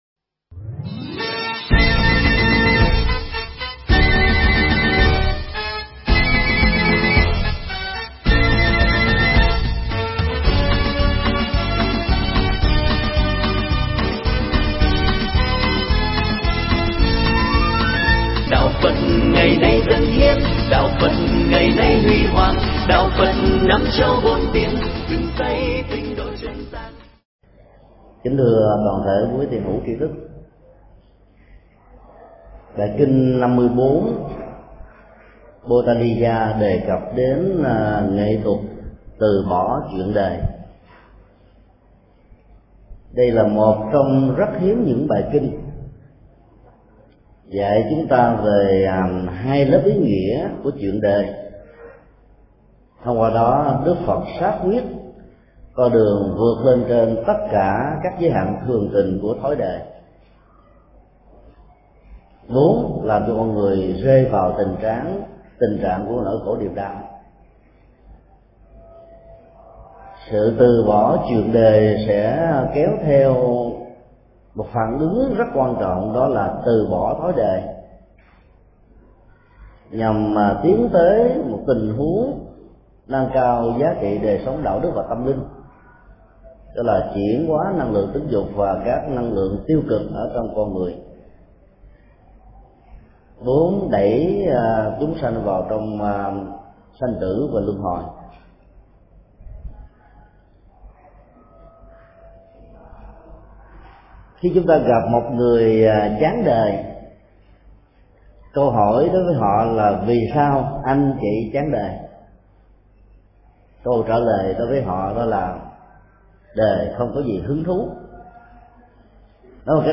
Nghe mp3 Thuyết Pháp Kinh Trung Bộ 54
Giảng tại Chùa Xá Lợi